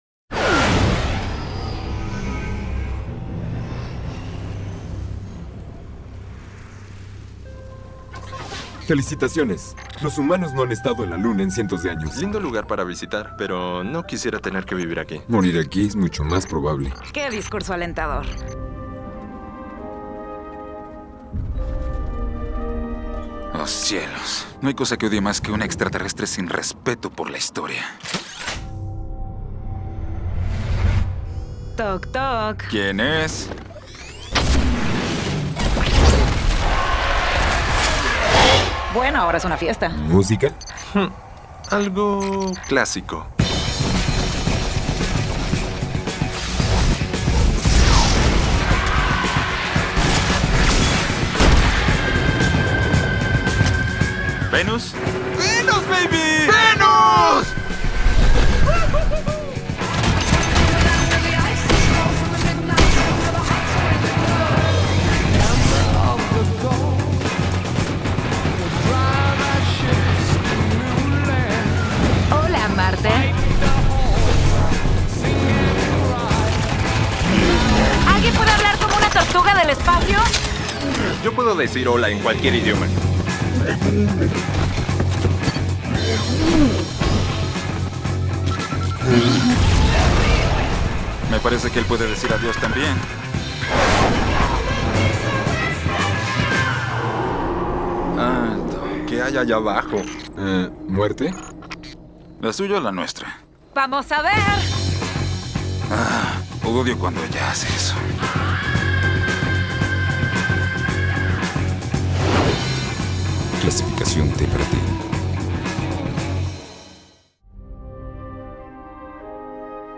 I have a clear voice and the ability to use various tones, accents, and emotions to convey material properly.
broadcast level home studio